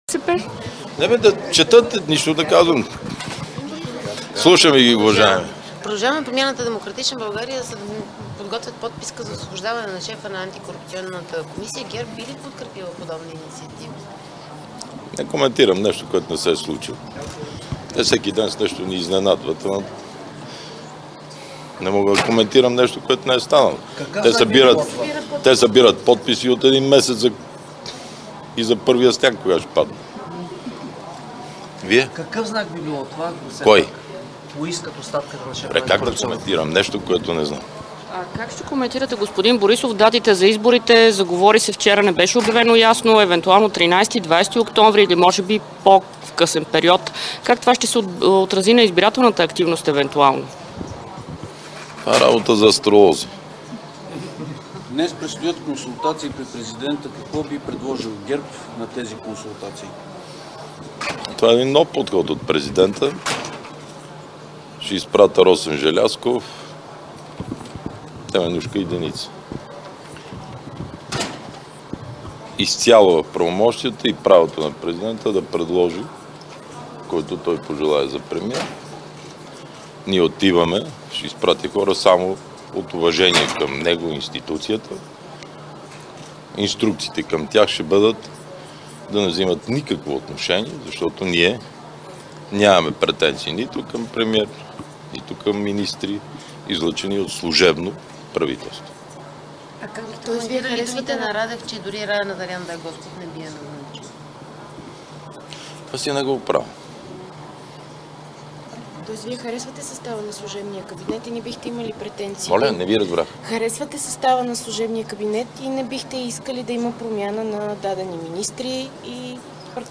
9.05 - Заседание на Народното събрание.
- директно от мястото на събитието (пл. „Княз Александър I" №1)
Директно от мястото на събитието